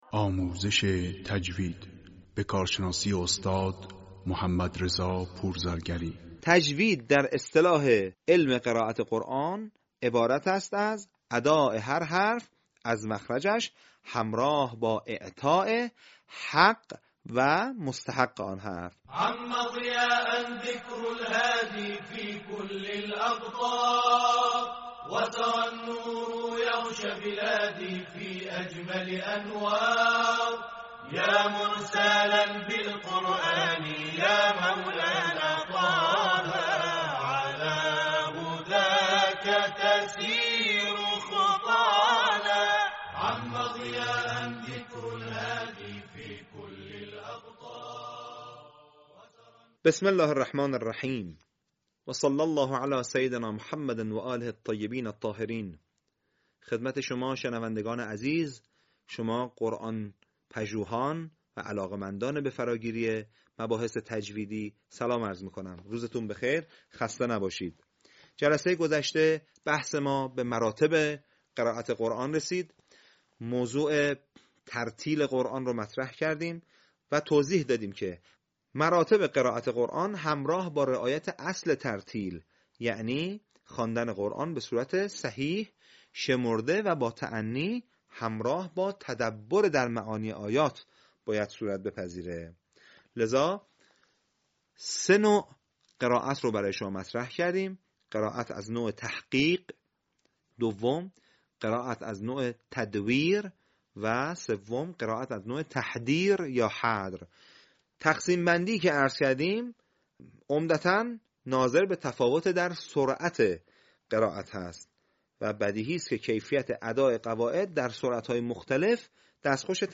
آموزش تجوید قرآن